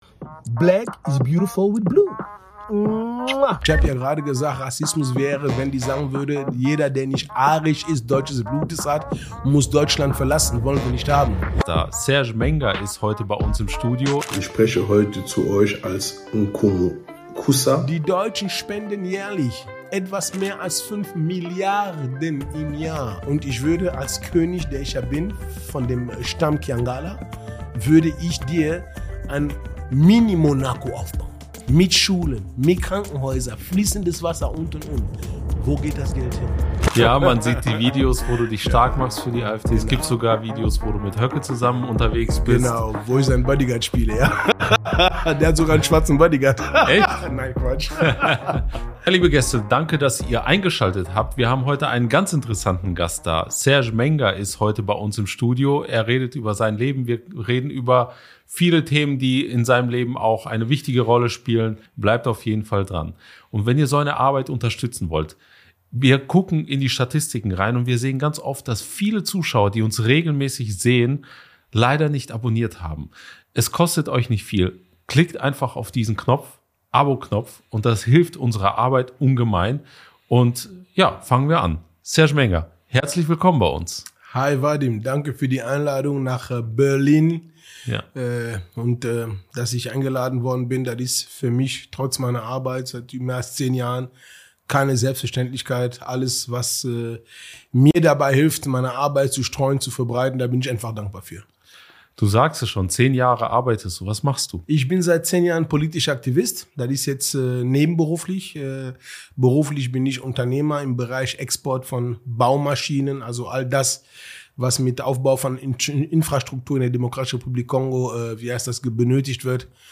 Exklusiv-Interview